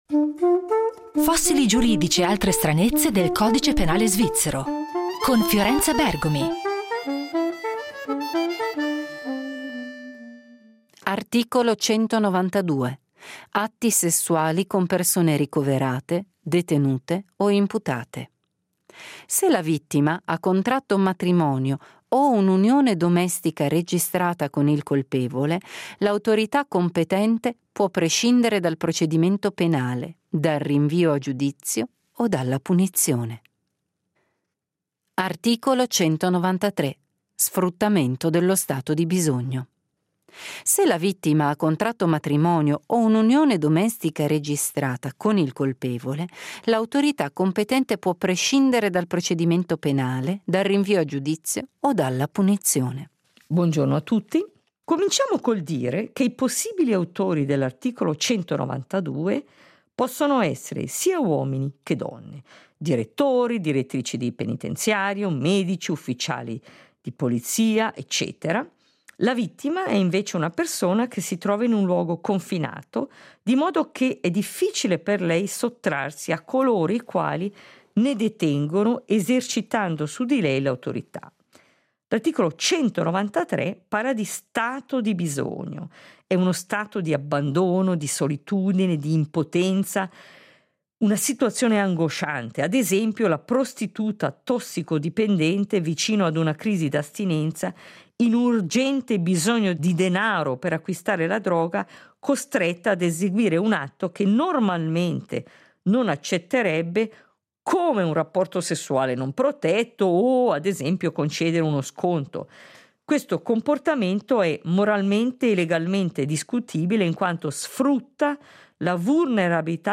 In questa miniserie andremo a caccia di questi elementi, commentandone la genesi e i motivi per cui ancora oggi fanno parte del nostro ordinamento. A guidarci in questo viaggio sarà la Giudice penale federale Fiorenza Bergomi.